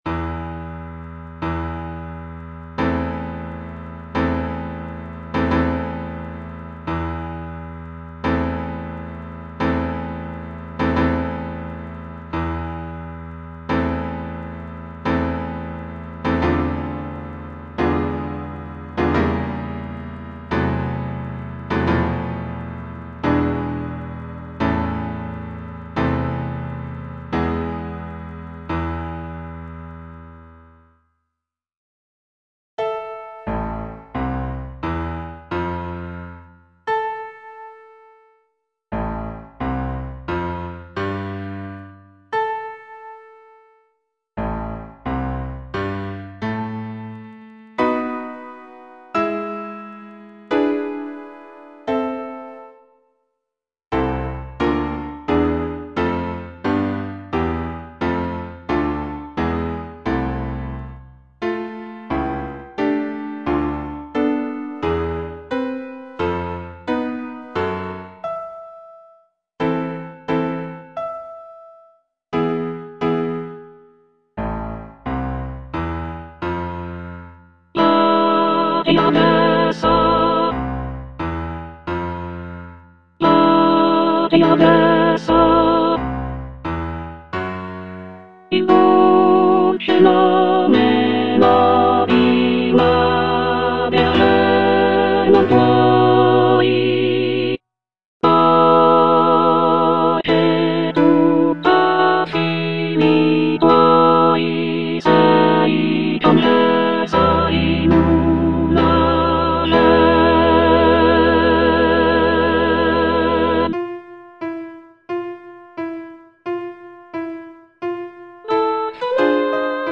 G. VERDI - CORO DI PROFUGHI SCOZZESI FROM "MACBETH" Soprano III (Emphasised voice and other voices) Ads stop: auto-stop
The piece features rich harmonies and powerful melodies that evoke a sense of sorrow and longing.